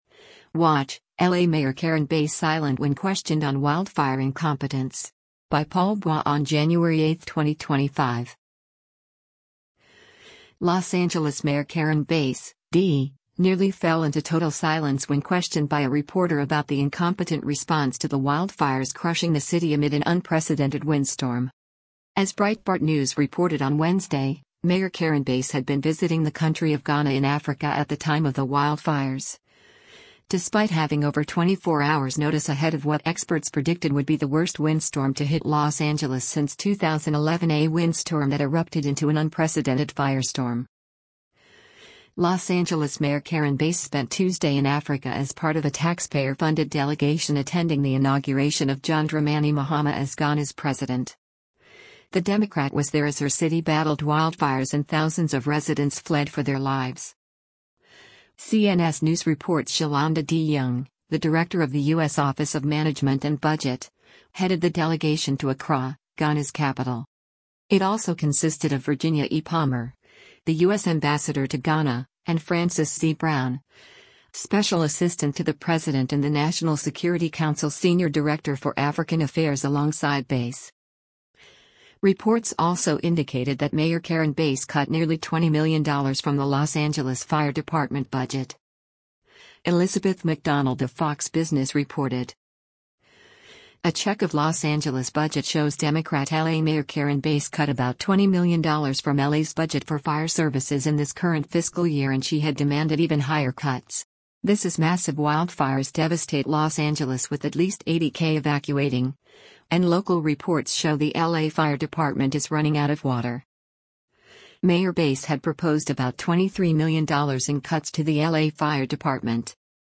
Los Angeles Mayor Karen Bass (D) nearly fell into total silence when questioned by a reporter about the incompetent response to the wildfires crushing the city amid an unprecedented windstorm.
When questioned about her response to the fire by a Sky News reporter on Wednesday, Bass appeared stonefaced and remained silent for nearly two minutes.